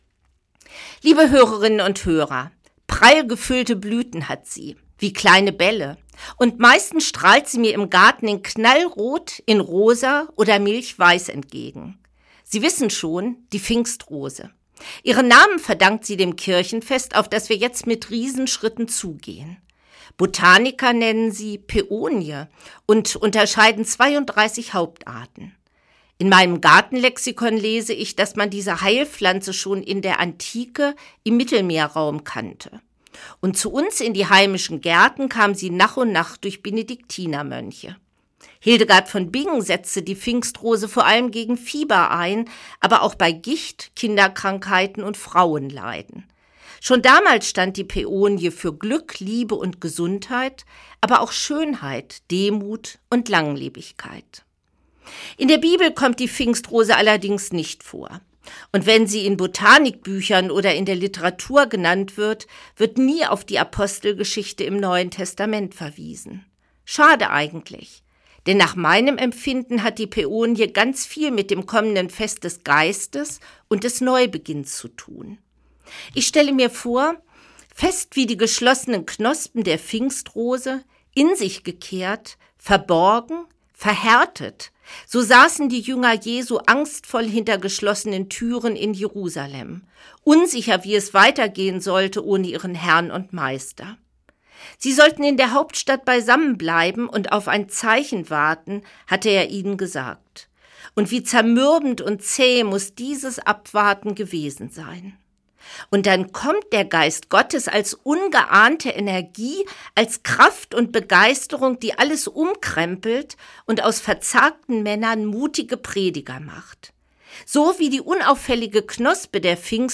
Radioandacht vom 16. Mai – radio aktiv